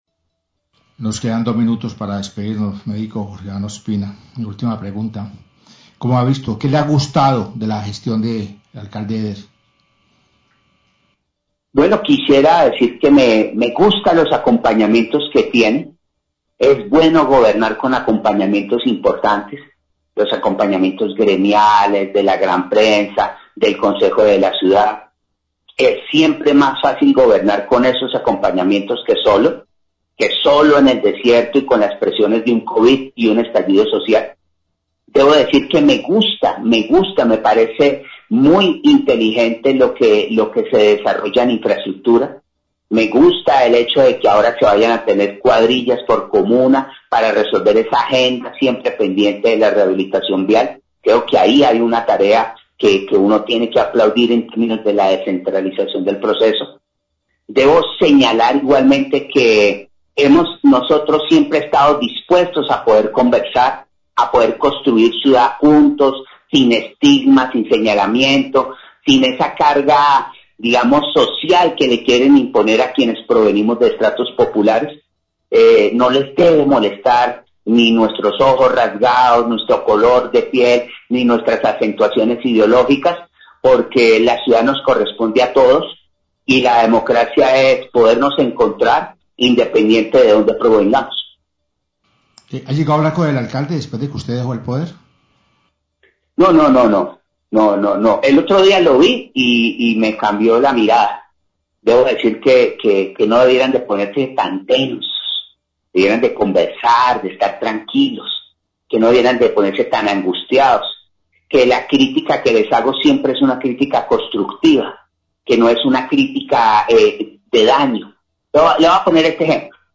Radio
Jorge Iván Ospina habla de lo que le gusta de la administración de Alejandro Eder, destacó las cuadrillas por comuna para la recuperación de las vías.